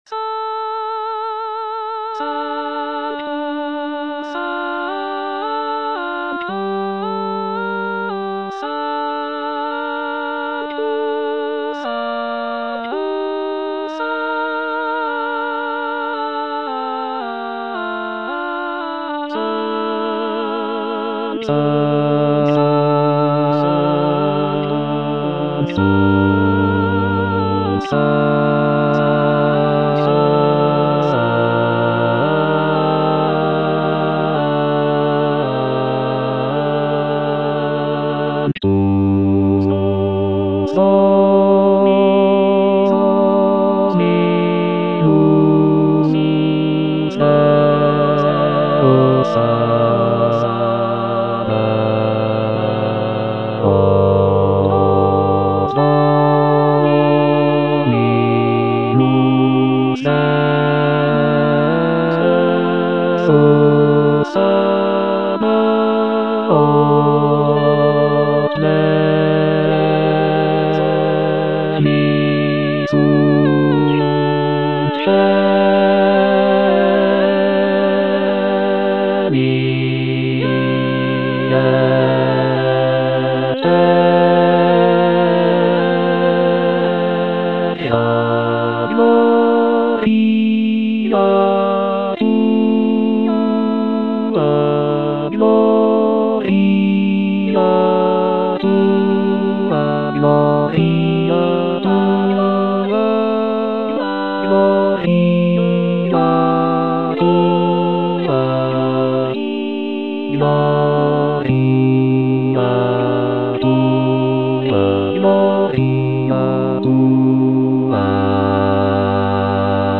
Bass (Emphasised voice and other voices) Ads stop